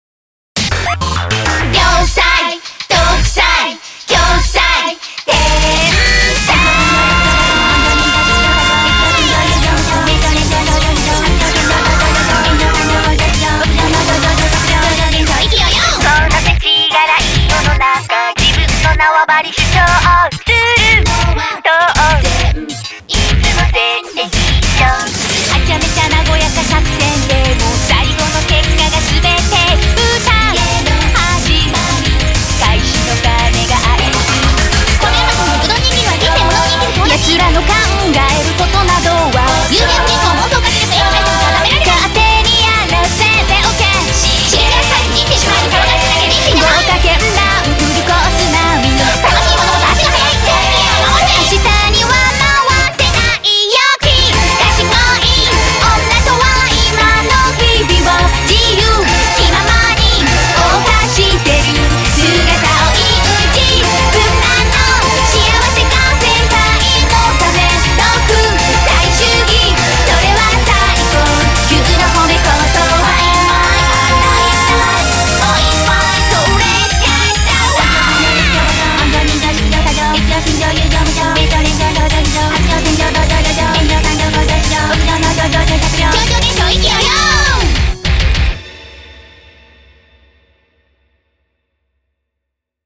BPM202
Audio QualityPerfect (High Quality)